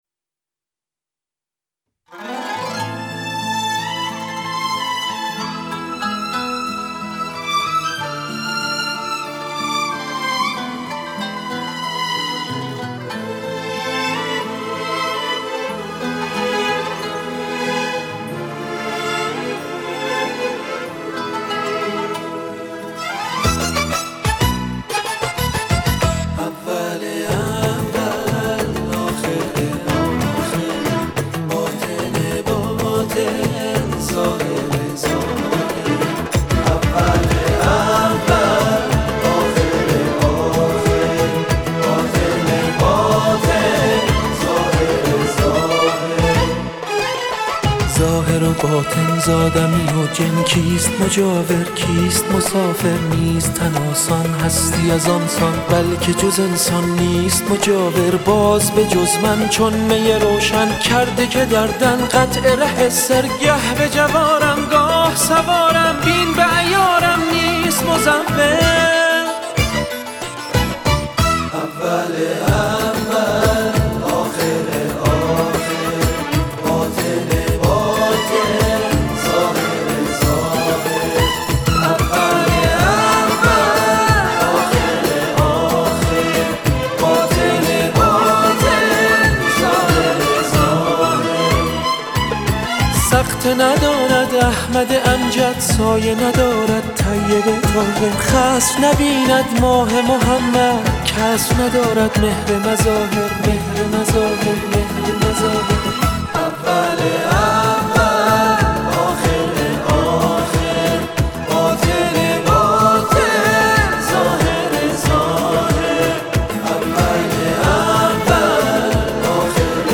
عیدانه